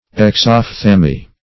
Exophthalmy \Ex`oph*thal"my\